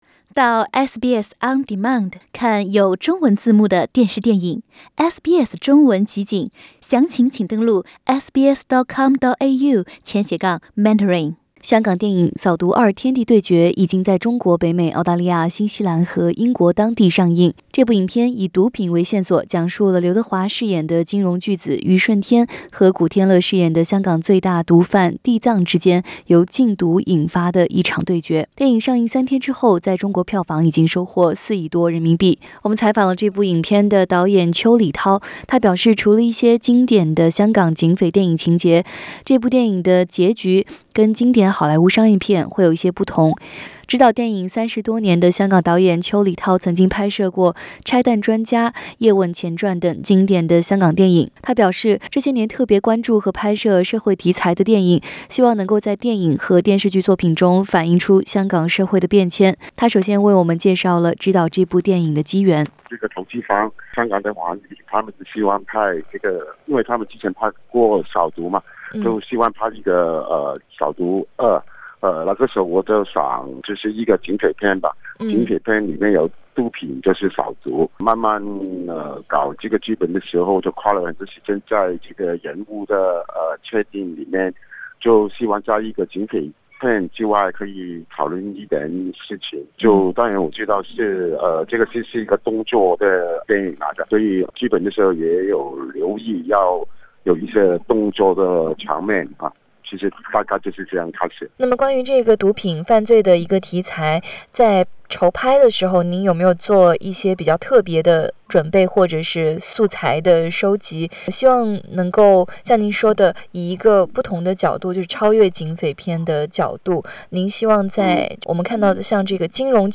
【SBS專訪】香港導演邱禮濤：心態單純的講反應香港社會變遷的故事